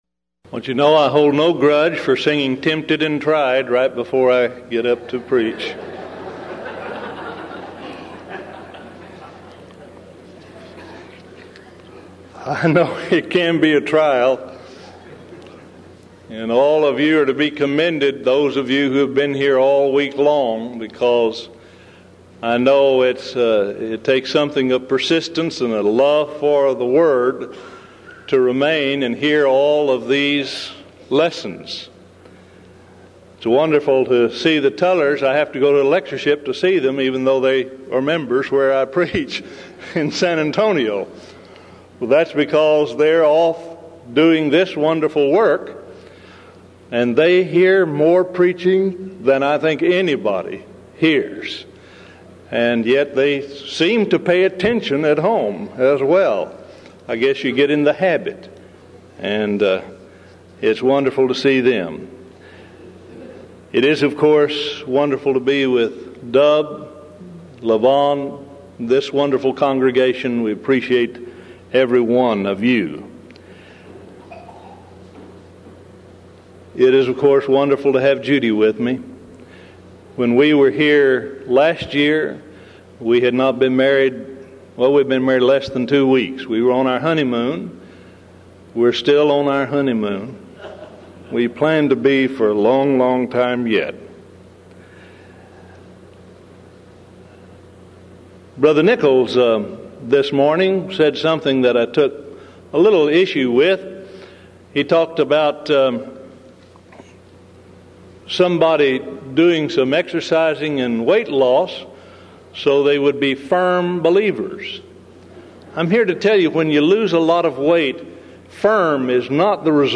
Event: 1996 Denton Lectures
lecture